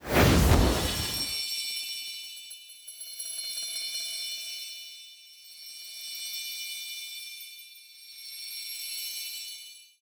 UI_Glyph_In.ogg